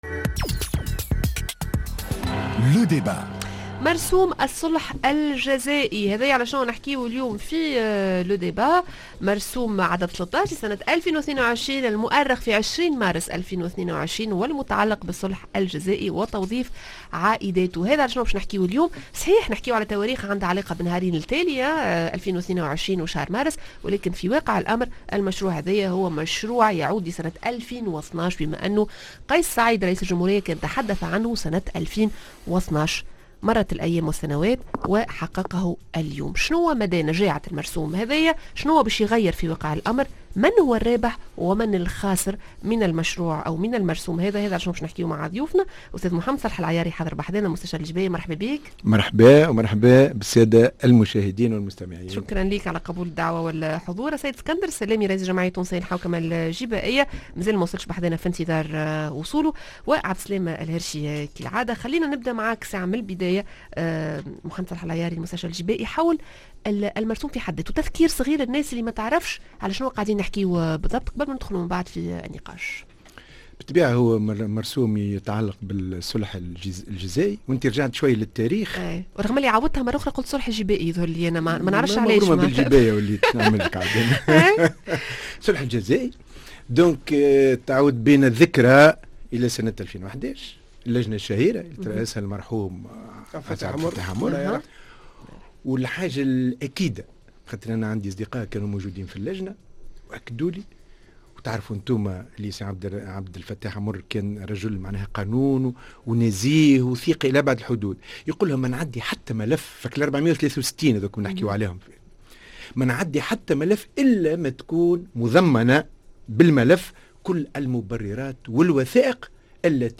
Le_débat:مرسوم الصلح الجزائي من الرابح و من الخاسر؟